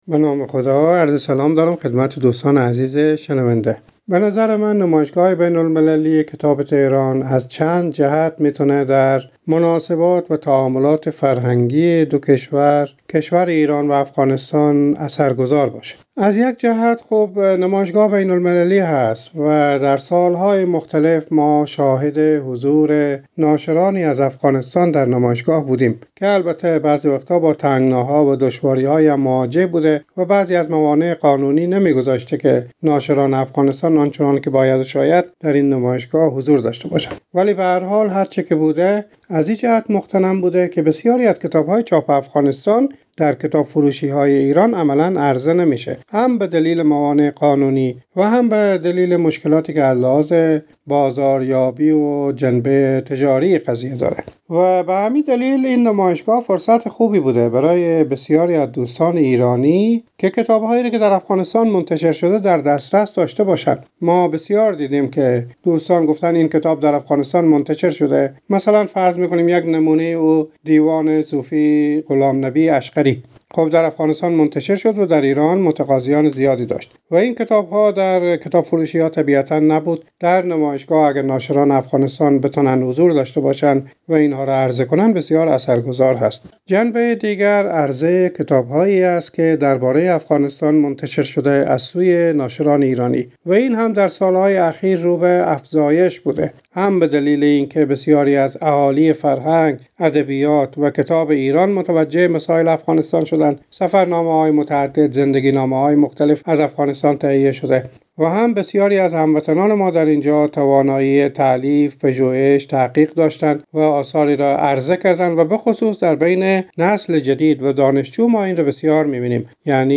در حاشیه برگزاری نمایشگاه کتاب تهران به خبرنگار رادیو دری افزود